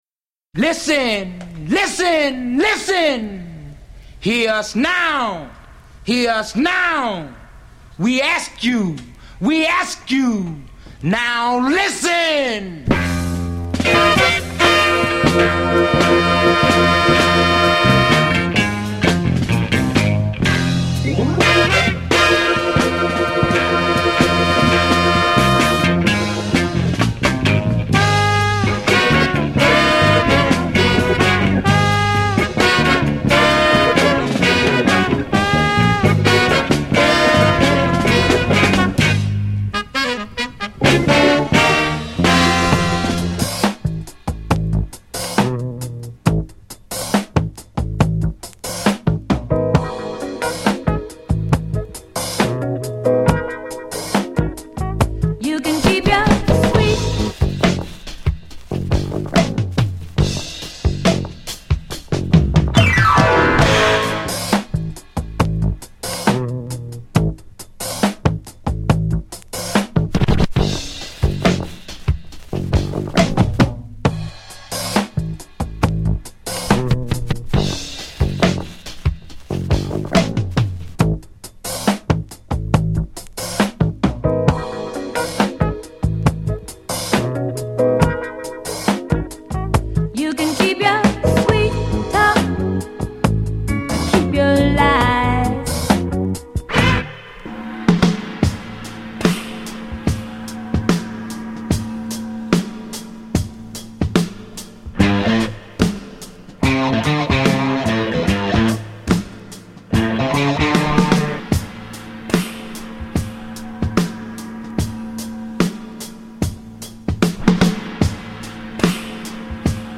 全体を通して感じさせる、クールさを兼ね備えたファンキーな質感が最高デス。
（BPM70～100位で80曲程収録）
*曲中のノイズはレコードによるものです。
※試聴はダイジェストです。